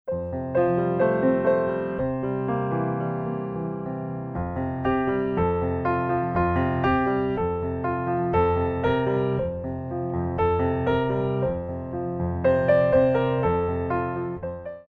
By Pianist & Ballet Accompanist
Piano selections include:
Slow Tendu